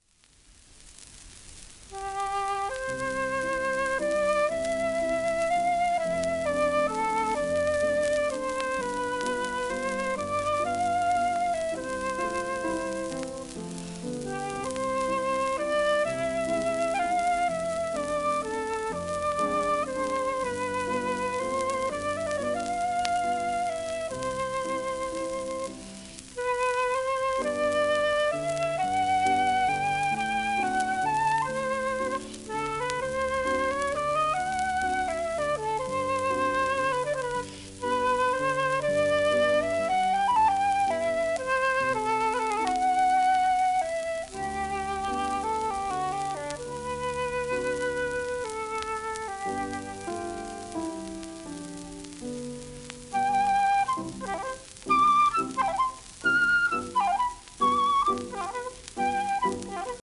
w/ピアノ